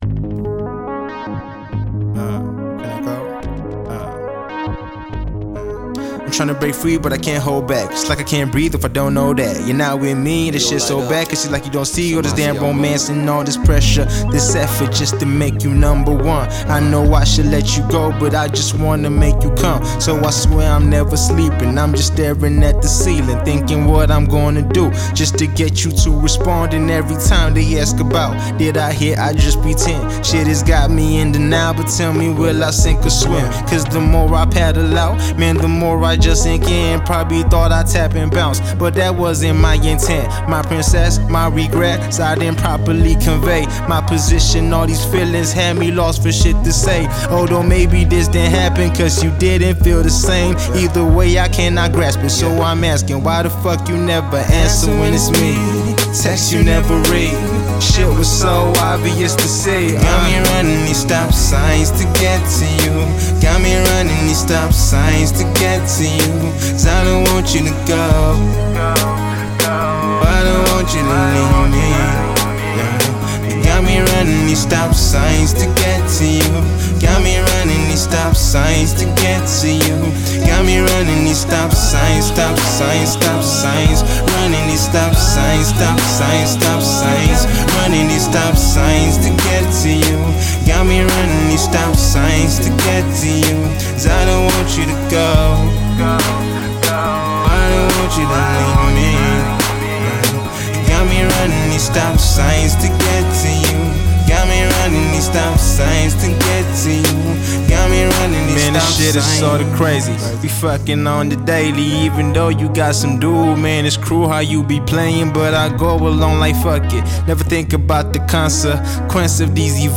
MC